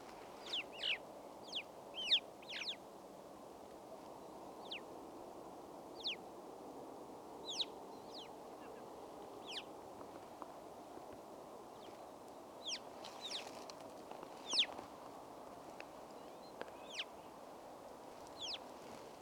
Havasi csóka (Pyrrhocorax graculus) hangja
A havasi csóka (Pyrrhocorax graculus) hangja jól felismerhető, éles és dallamos. Leggyakrabban hallható hangja egy „pí-pí” vagy „kví-kví” hang, amely könnyen megkülönböztethető a varjúfélék más tagjainak hangjától.
Ritmikus ismétlés: Gyakran ad ki sorozatos, gyorsan ismétlődő hangokat, amelyek a csoporton belüli összetartozást erősítik.
Holló (Corvus corax): A holló mélyebb, rekedtebb hangokat hallat, míg a havasi csóka hangja élesebb és dallamosabb.